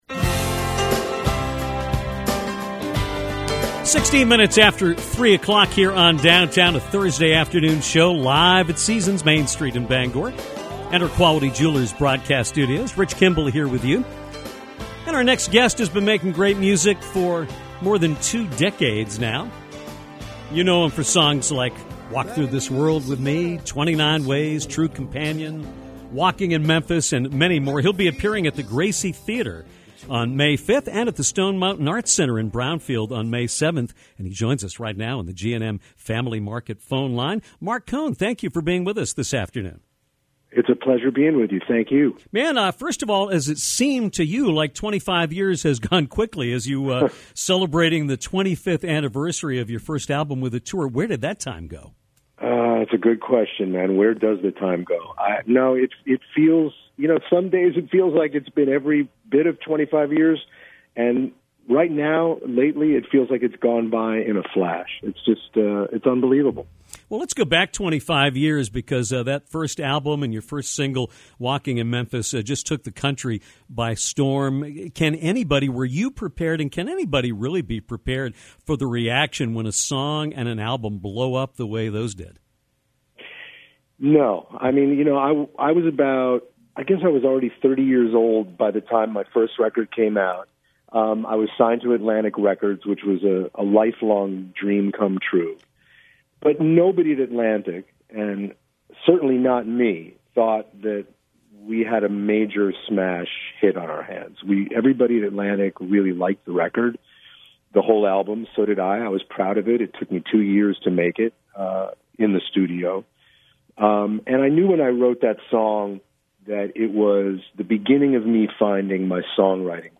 Singer-songwriter Marc Cohn joined Downtown to discuss his current tour, which celebrates the 25th anniversary of his debut album. Cohn talked about how quickly the time has passed, shared the story behind his classic song “Walking In Memphis”, and reflected on some of the talented artists he has collaborated with through the years. He also recalled the experience of being shot in the head during an attempted car-jacking and how music helped him process all that happened.